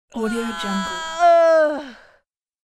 دانلود افکت صوتی ناله زن از شدت درد
Female Big Effort Moan 2 royalty free audio track is a great option for any project that requires human sounds and other aspects such as a sfx, voice and sound.
Sample rate 16-Bit Stereo, 44.1 kHz
Looped No